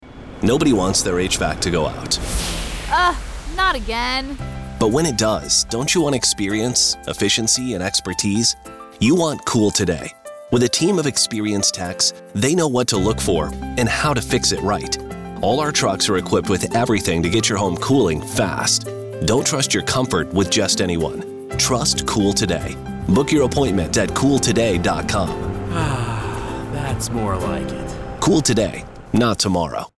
ad samples.
HVAC-Commercial.mp3